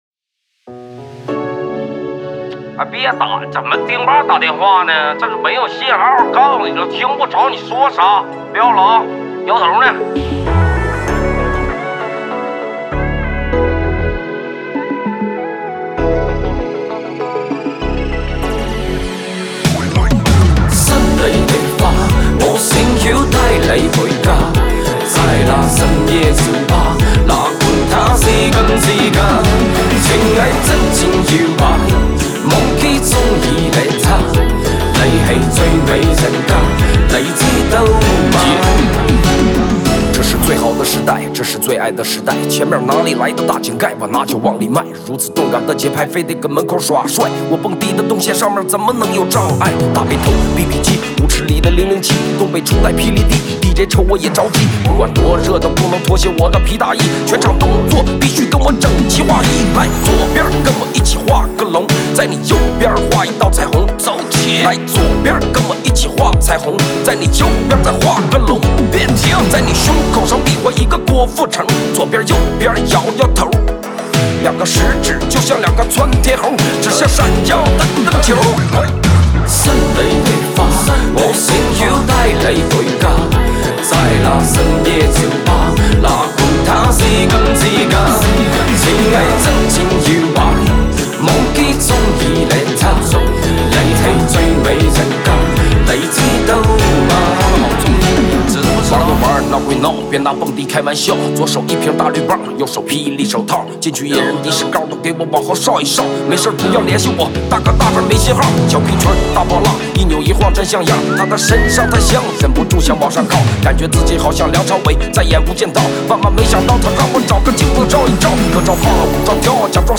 Ps：在线试听为压缩音质节选，体验无损音质请下载完整版
它混搭着港台金曲风，东北喊麦的市侩劲儿和复古迪斯科的强劲节奏，有一种真诚的土味 。